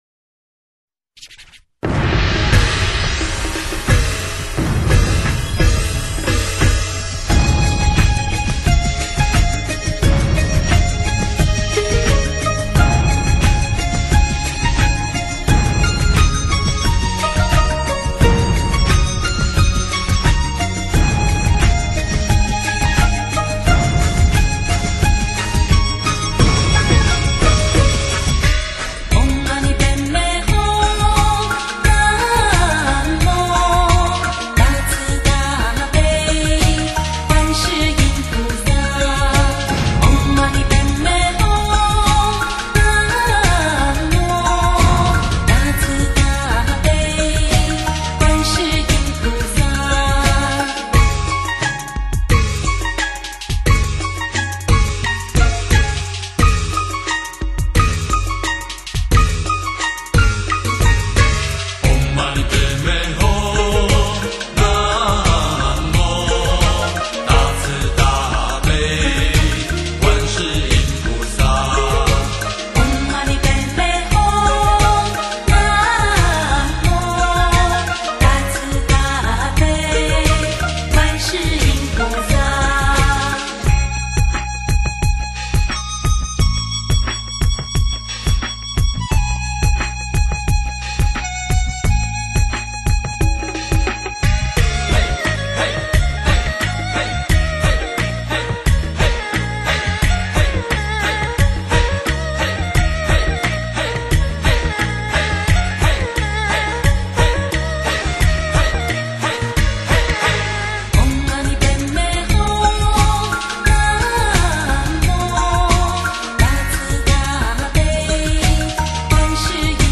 柔和清涼的声音中，听着听着就会感觉到心境好像处在充满清香的莲花大海之中，
是那么的温柔，那么的清涼，那么的清明，心情马上就会平静下來。